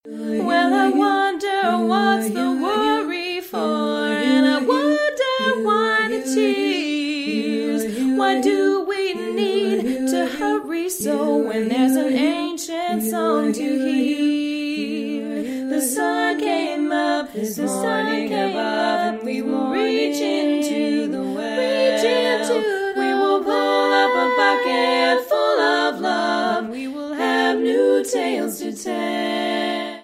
SSAA or SATB